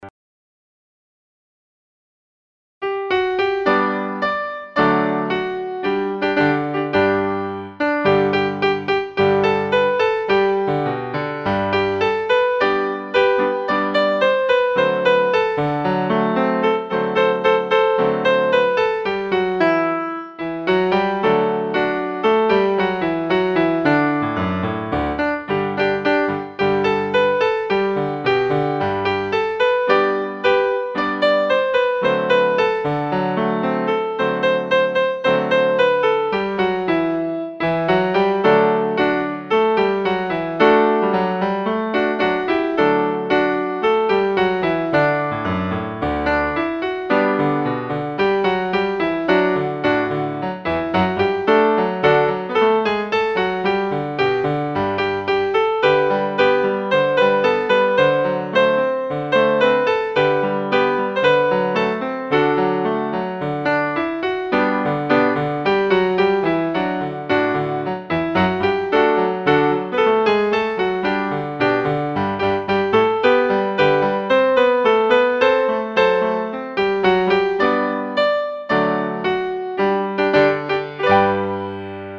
Piano Accompaniment / Singing Melody / Twin Version / School Song
piano_accompaniment.mp3